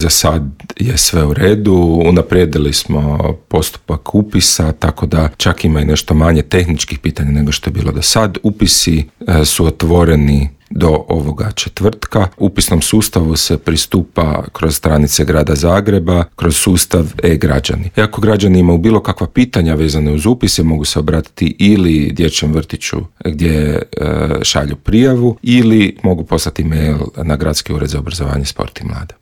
Zahtjevi za upis djece u zagrebačke vrtiće provode se elektroničkim putem, a zajedno sa svom potrebnom dokumentacijom mogu se predati do 22. svibnja, podsjeća u intervjuu Media servisa pročelnik Gradskog ured za obrazovanje, sport i mlade Luka Juroš.